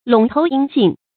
陇头音信 lǒng tóu yīn xìn
陇头音信发音
成语注音ㄌㄨㄙˇ ㄊㄡˊ ㄧㄣ ㄒㄧㄣˋ